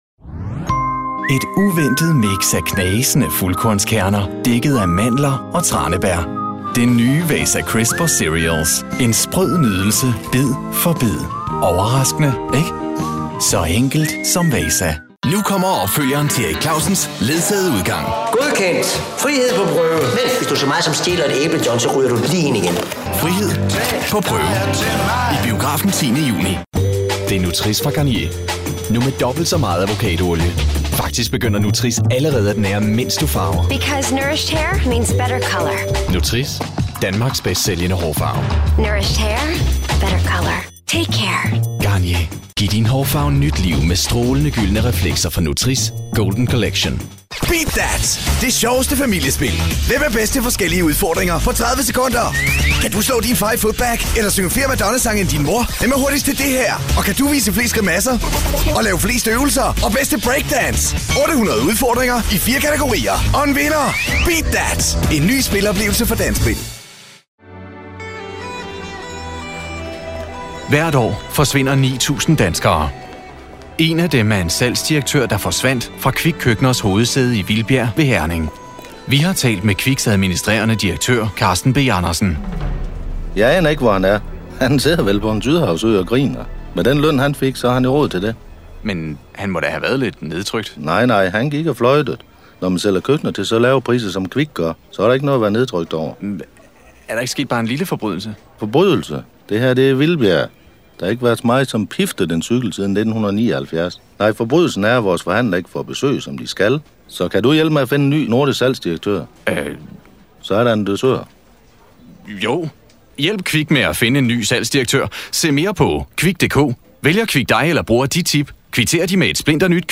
Professional danish voiceover artist with a very versatile voice. Voice characters from warm & trustworthy to upbeat / crazy.
Sprechprobe: Werbung (Muttersprache):